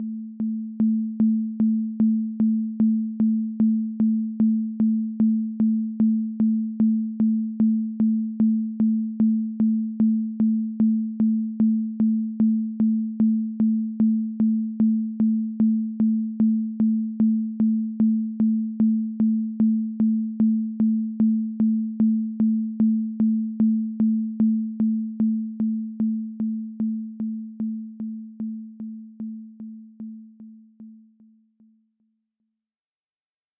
Voici deux fichiers mp3 avec des SBA lentes et rapides en audio SBA rapides (300 pbm) :
sba-rapide-300pbm.mp3